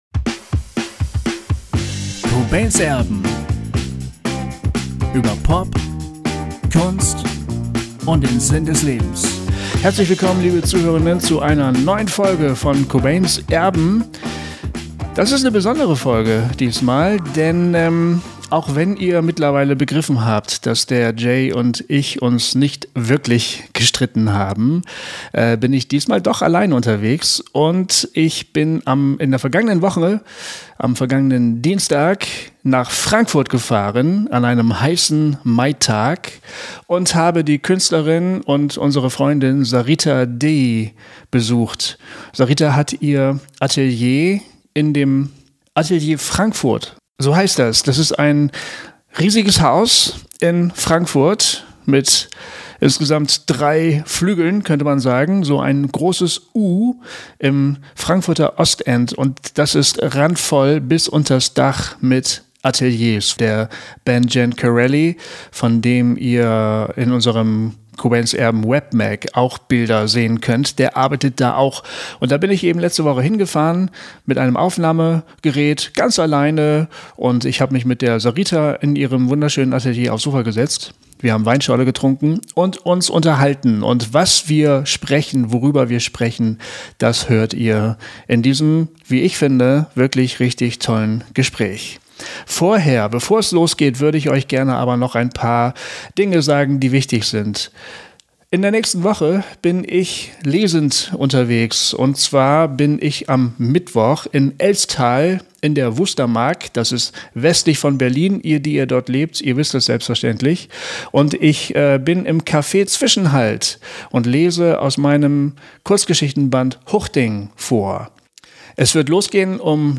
Wie sie das ganz konkret erlebt, das schildert sie in diesem Gespräch.